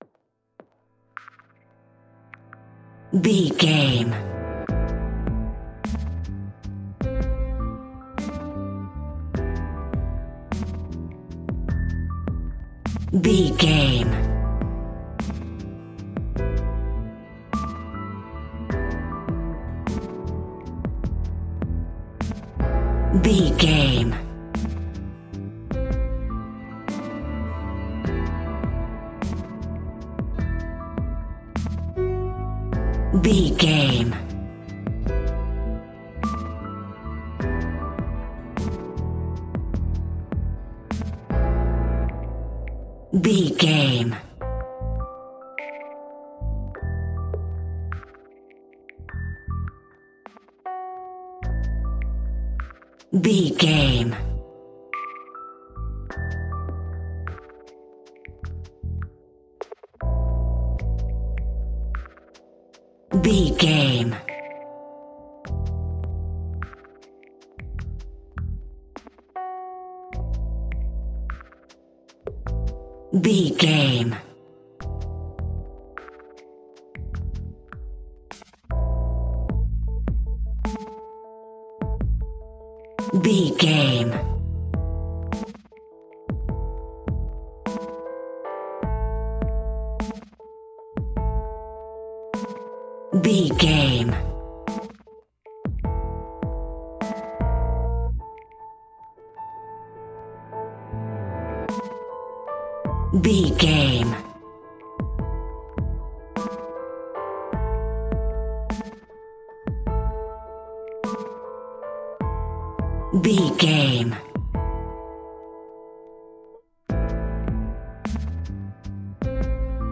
Epic / Action
Fast paced
In-crescendo
Uplifting
Ionian/Major
A♭
hip hop
instrumentals